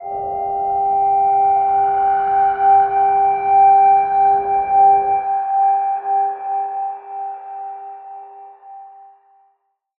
G_Crystal-G5-pp.wav